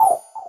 select-expand.wav